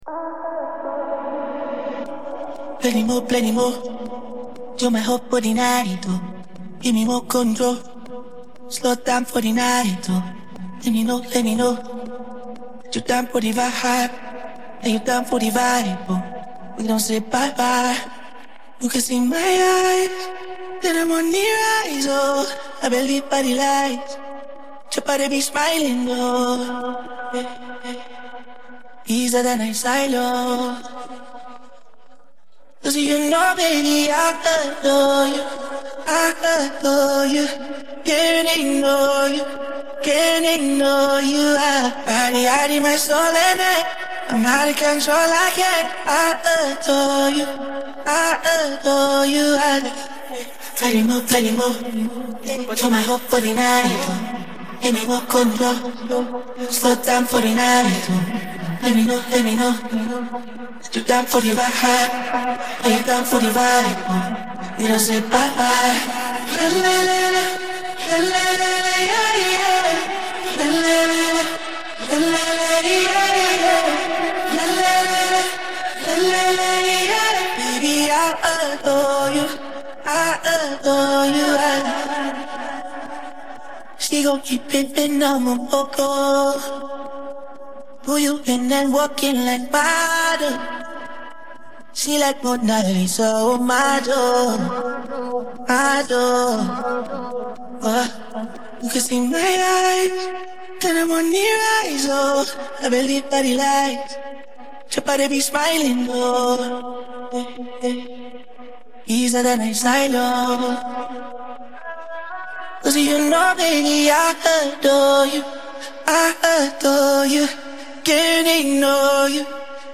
Голосовая часть